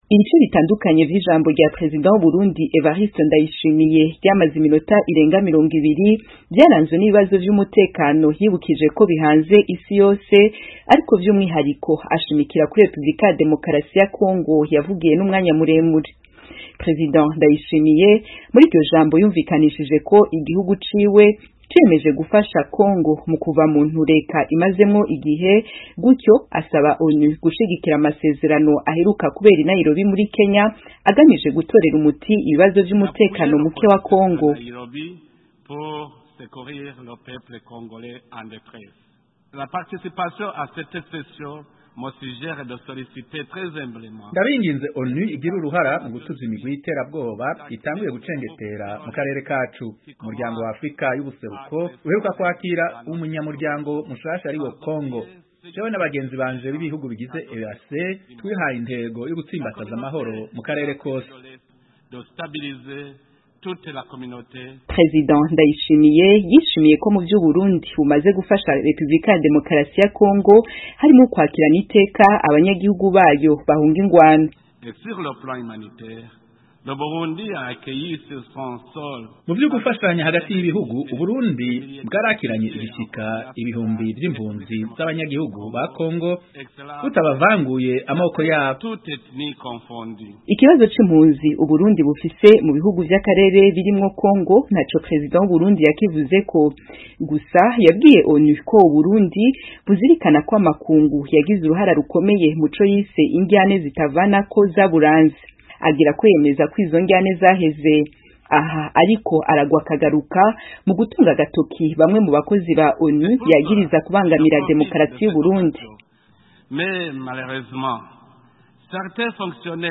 Ijambo Prezida Ndayishimiye Yavugiye muri ONU